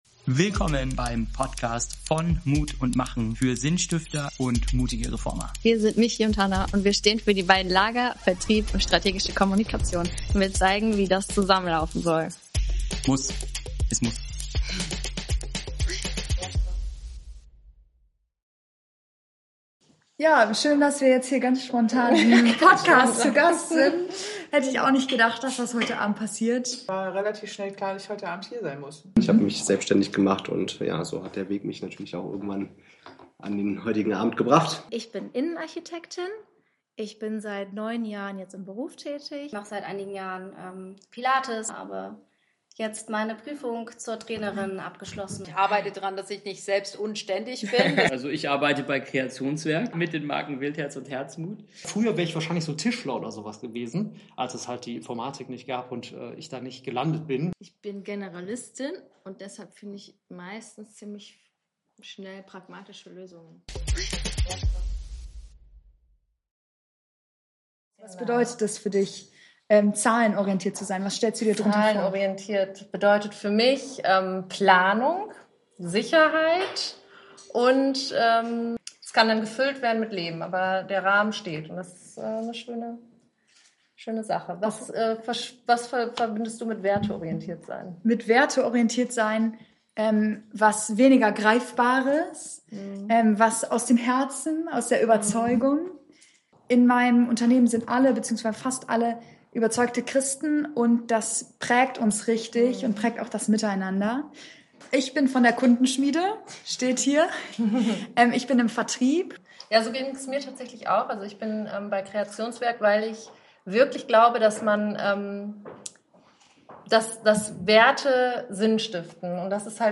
Diese Folge ist ganz besonders: Sie wurde live beim von Mut und Machen Event am 30-10-25 aufgenommen – mitten zwischen Gesprächen, Lachen, Fragen und ehrlichen Momenten.
So Spannend zu sehen, welche unterschiedlichen Perspektiven Gründerinnen, Kreative und Sinnstifter mitbringen. Menschen, die über das sprechen, was sie antreibt – und über die Zweifel, die dazugehören.